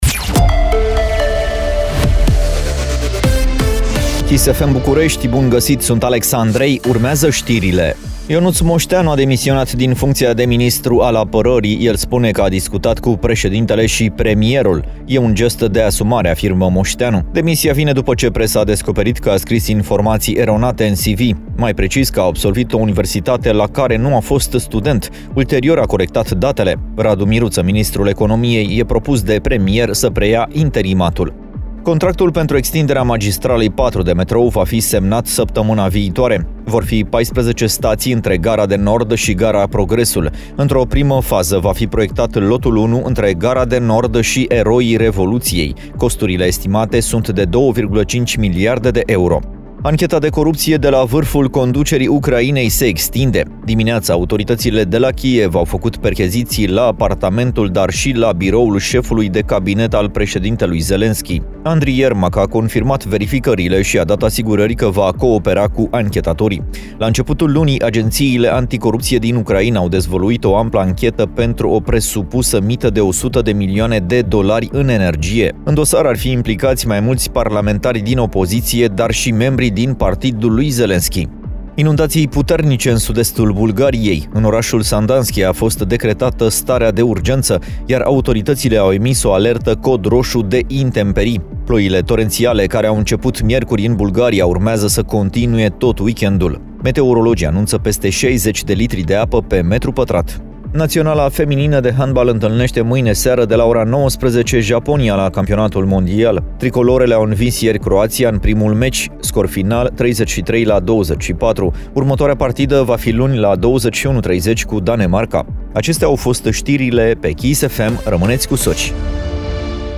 Știrile zilei de la Kiss FM